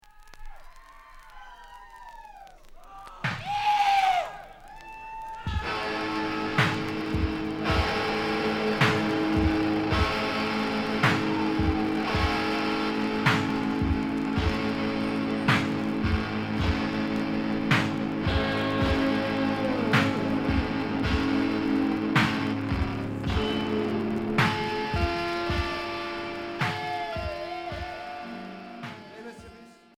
Rock Unique 45t